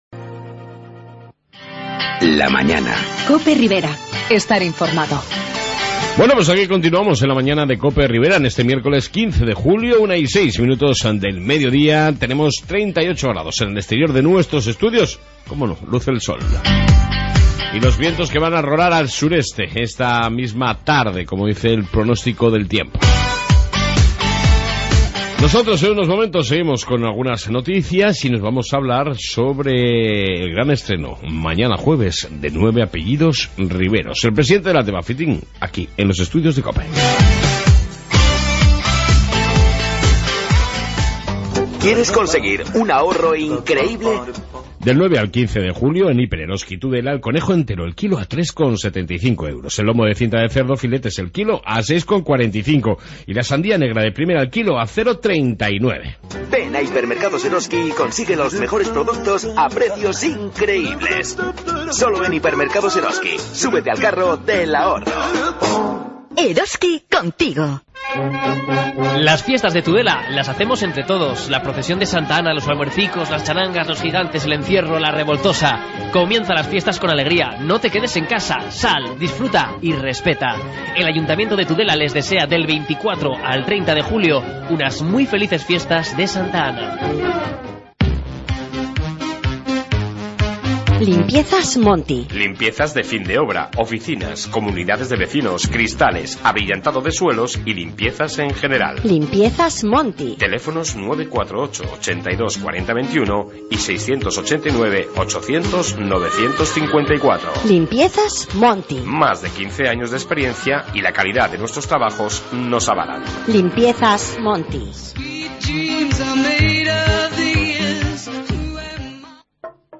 Noticias Riberas y entrevista